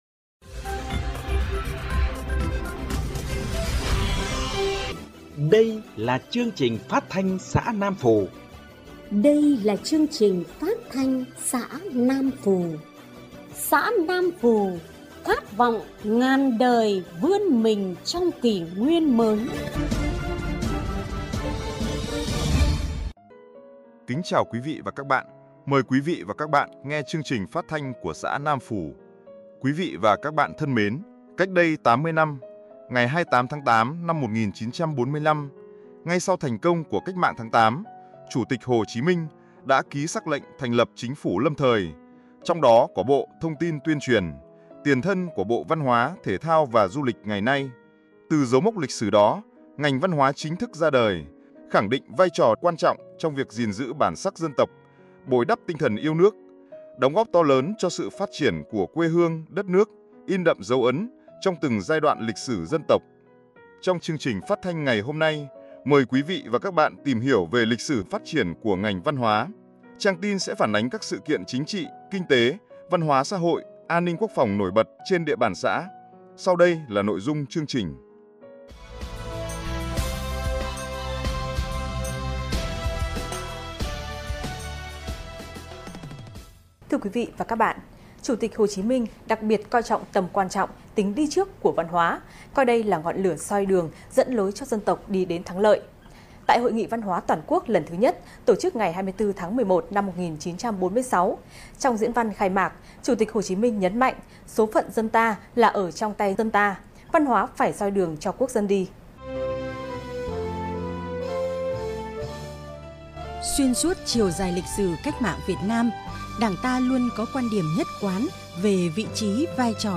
Chương trình phát thanh xã Nam Phù ngày 27/8/2025